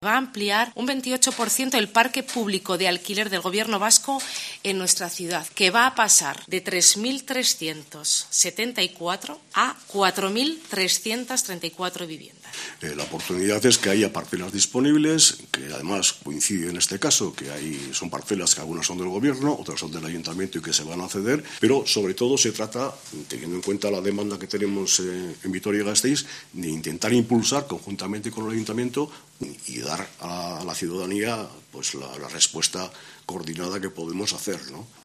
Iñaki Arriola, consejero de Vivienda, y Maider Etxebarria, alcaldesa de Vitoria, sobre vivienda